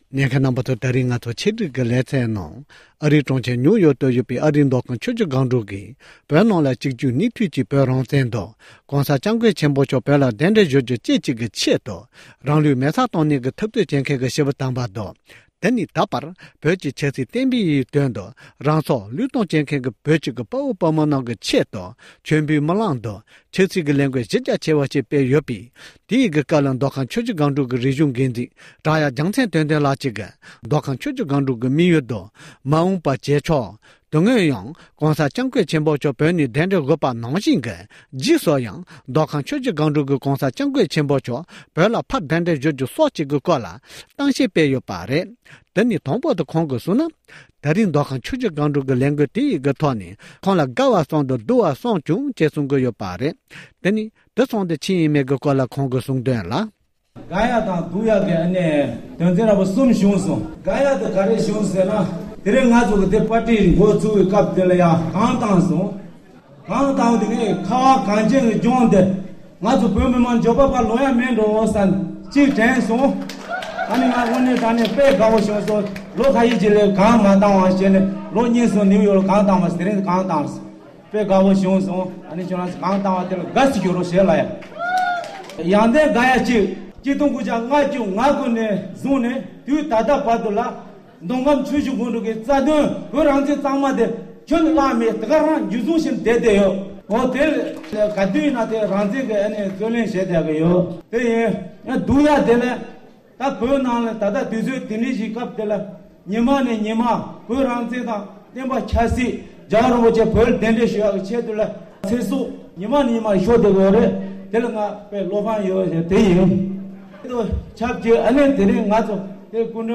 ནིའུ་ཡོརྐ་མདོ་ཁམས་ཆུ་བཞི་སྒང་དྲུག་གིས་བོད་ནང་རང་ལུས་མེར་སྲེག་གཏོང་མཁན་རྣམས་ལ་མཆོ་འབུལ་སྨོན་ལམ་འཚོགས་པ།
སྒྲ་ལྡན་གསར་འགྱུར།